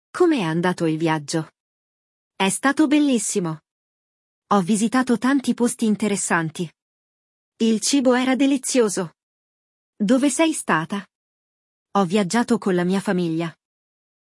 No episódio de hoje, você vai aprender como falar sobre suas viagens e férias em italiano. Acompanhe uma conversa entre duas amigas que se encontram por acaso e compartilham experiências sobre a última viagem de uma delas.